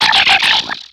Cri de Maskadra dans Pokémon X et Y.